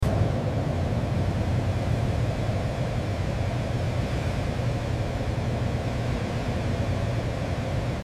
lion Unbliveabile Stunts CCTV Record